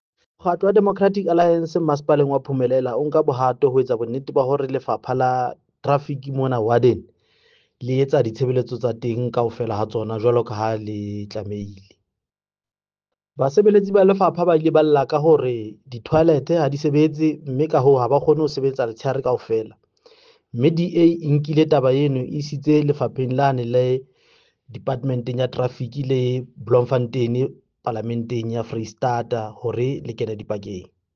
Sesotho soundbite by Cllr Diphapang Mofokeng.